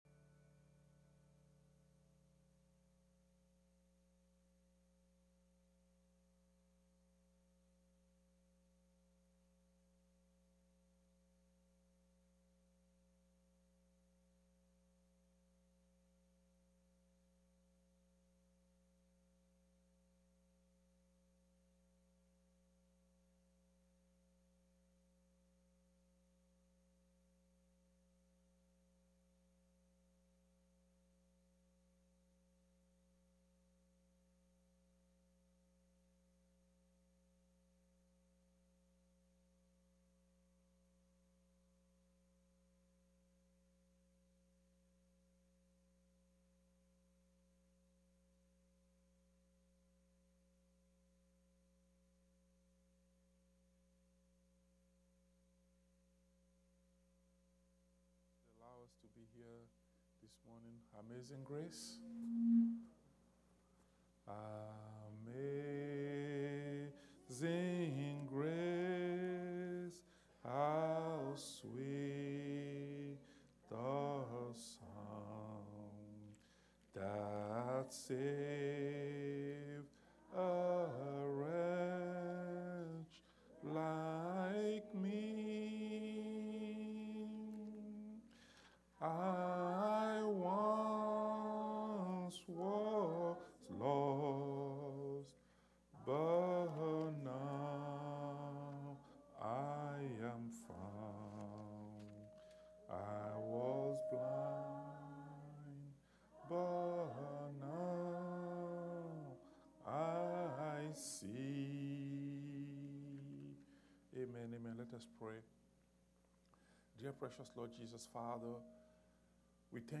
Rapturing Faith (Sunday school)